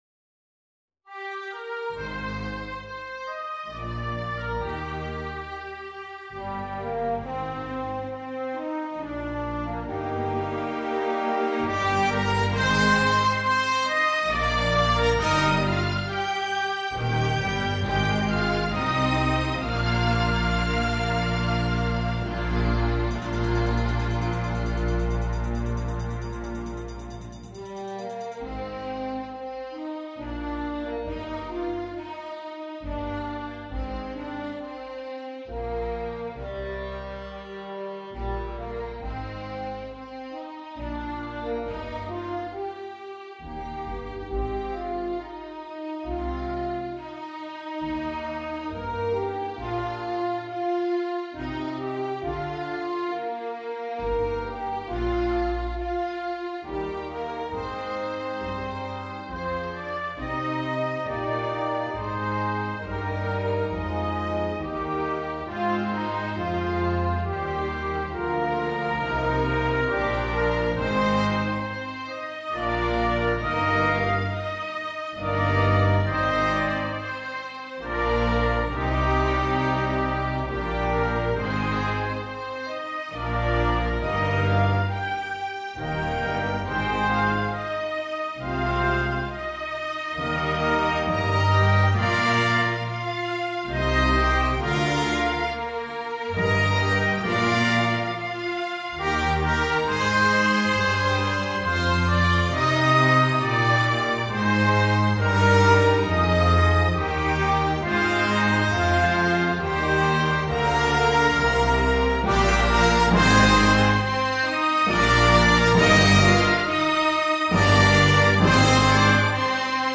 2. Symphony Orchestra
with reduced instrumentation
without solo instrument
Classical
2 x F Horn
1 x Timpani
6 x Violin 1